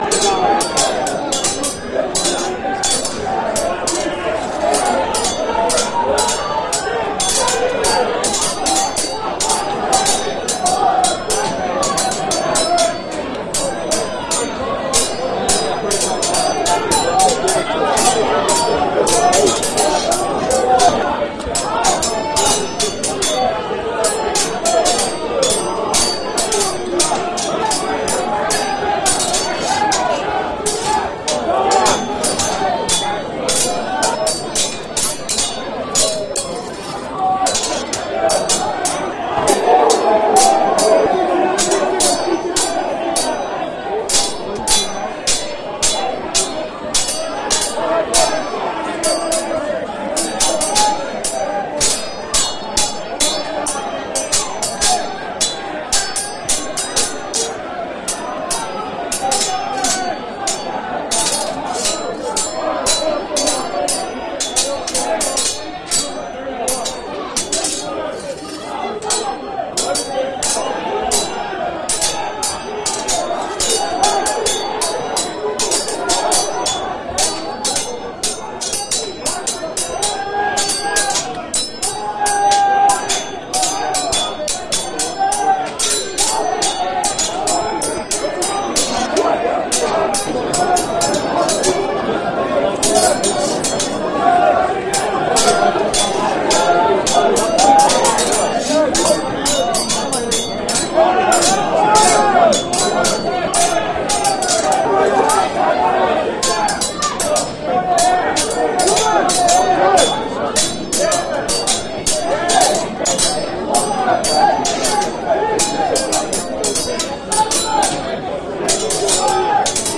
Old Speaker Static Noise Loop
2g analog artifact bruh noise old oldtune silence sound effect free sound royalty free Voices